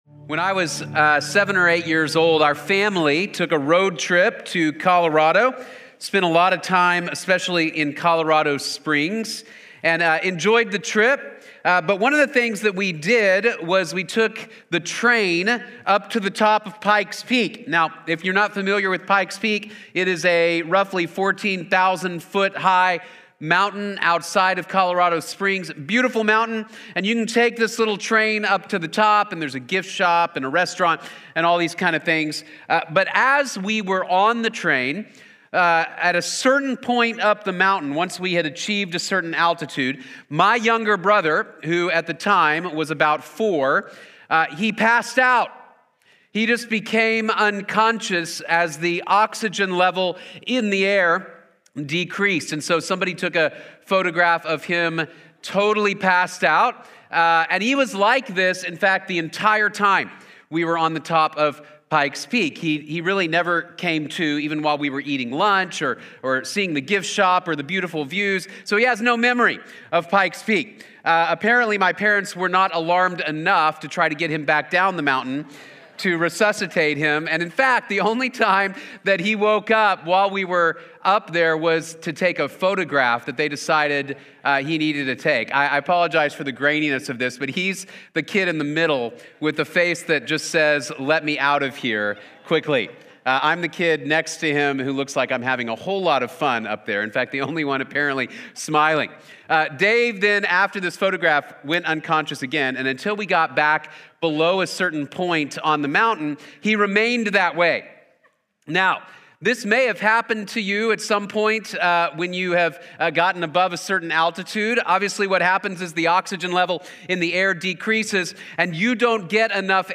The Spirit Gives Life | Sermon | Grace Bible Church